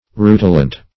Search Result for " rutilant" : The Collaborative International Dictionary of English v.0.48: Rutilant \Ru"ti*lant\, a. [L. rutilans, p. pr. of rutilare to have a reddish glow, fr. rutilus red: cf. F. rutilant.]